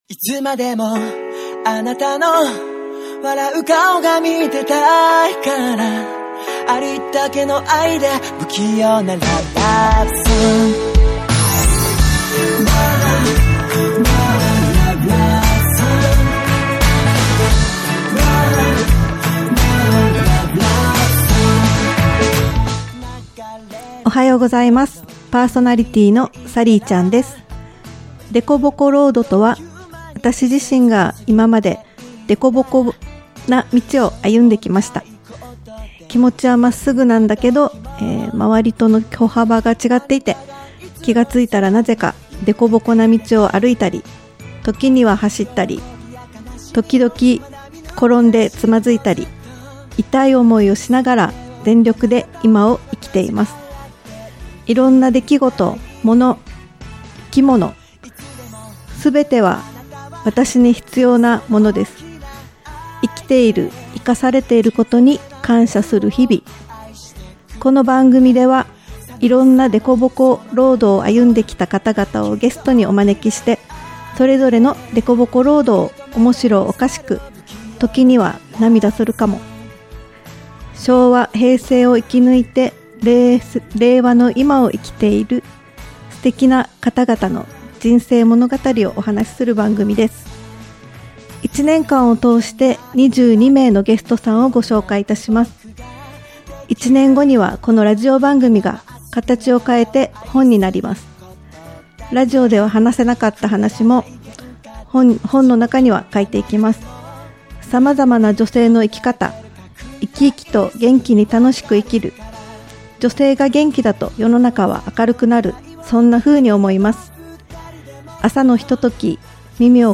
ゆめのたね | 〜ご縁・応援・貢献〜 あなたの夢を応援するインターネットラジオ局 「ご縁・応援・貢献」をテーマに夢ある番組をお届けするインターネットラジオ局。